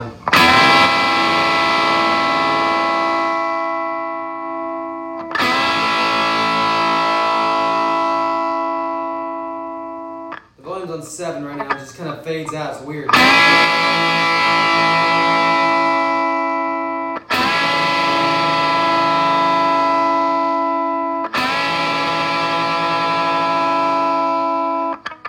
On certain notes, I hear a brief electronic fizz or crackle at the attack. The fizz fades quickly, but the note itself continues to sustain normally. I have a sound clip that I recorded on my phone and will try to upload it below. Guitar is straight in. No effects loop. The Marshall 1959 HW volume is on 7.